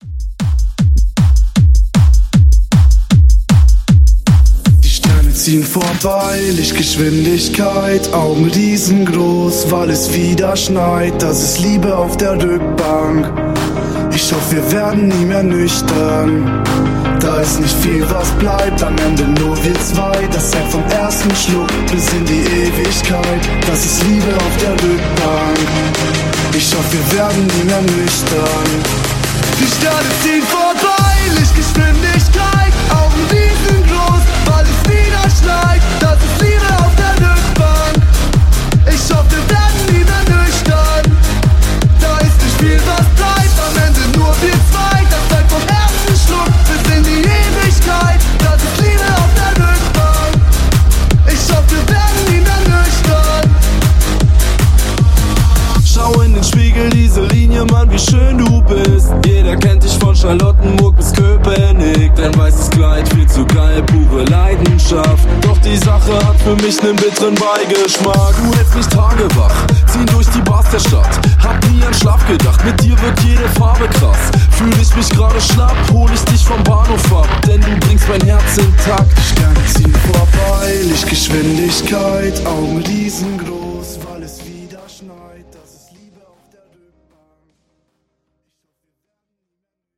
BPM: 155 Time